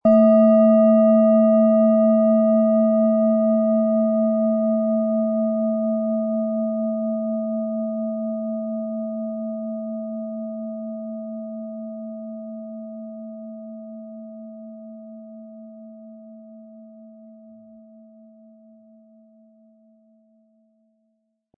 Planetenton 1
Thetawelle
Sie sehen und hören eine von Hand gefertigt Thetawelle Klangschale.
Um den Originalton der Schale anzuhören, gehen Sie bitte zu unserer Klangaufnahme unter dem Produktbild.